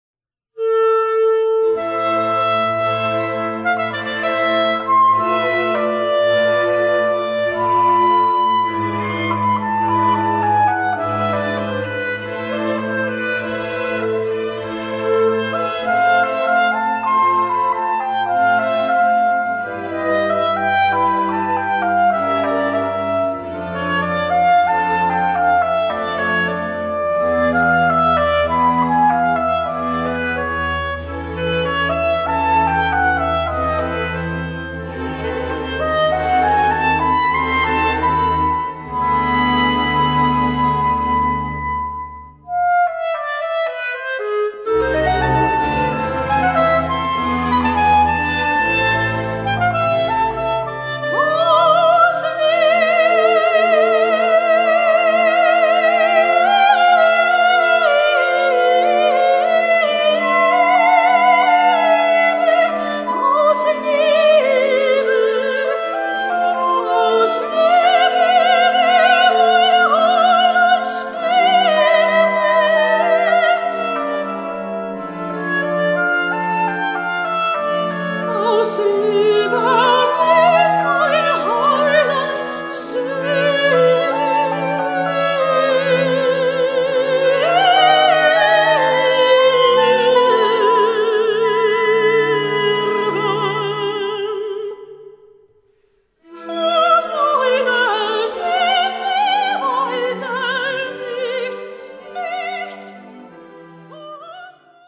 Voicing: Soprano, Clarinet and Orchestra